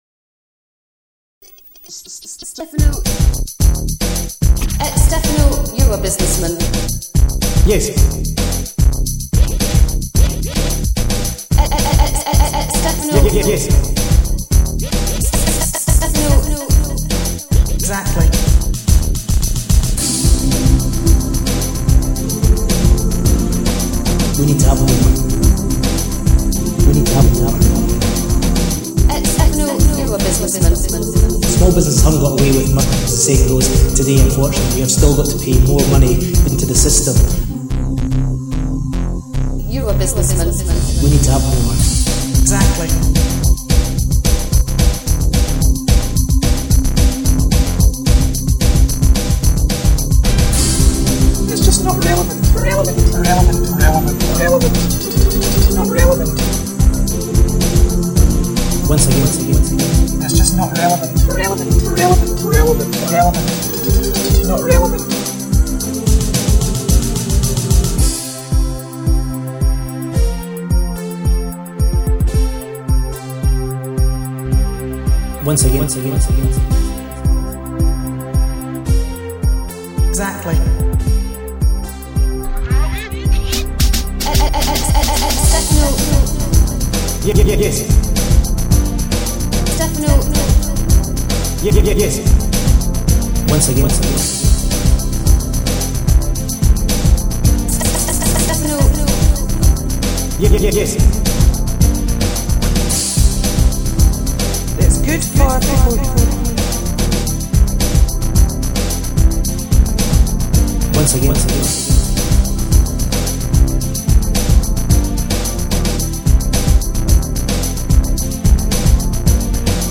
done with a PC, an SBlive soundcard, midi keyboard and PC software